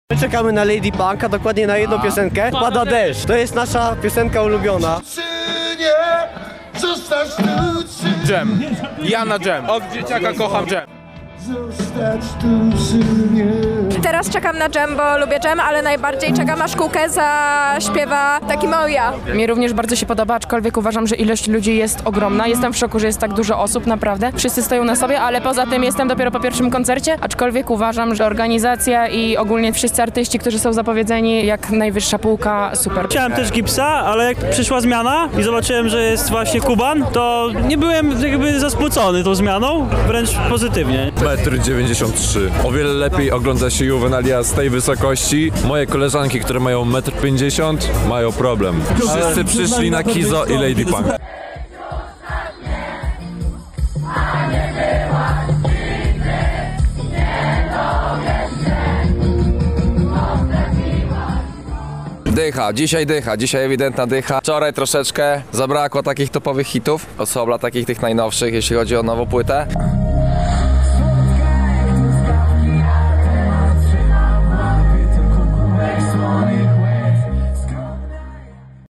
Relacja z Juwenaliów